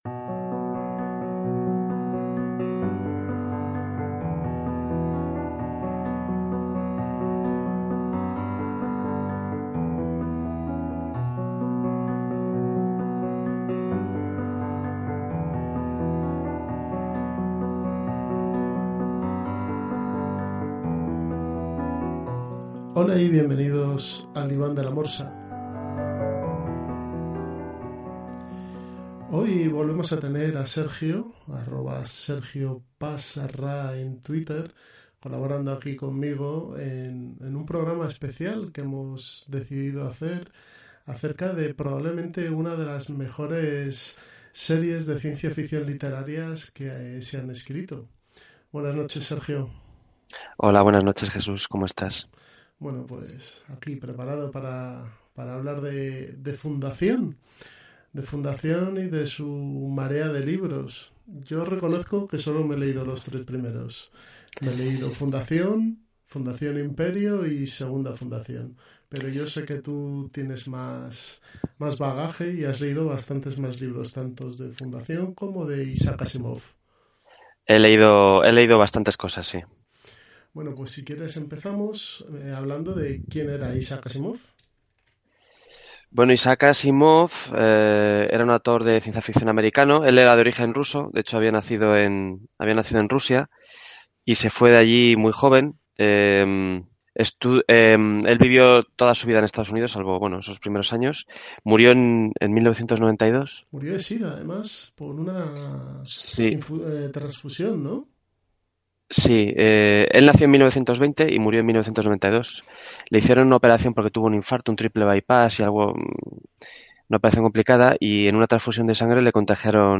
También comentamos acerca de las series y comics que estamos leyendo. Disculpad el sonido, sobre todo de mi micro, pero suena robótico.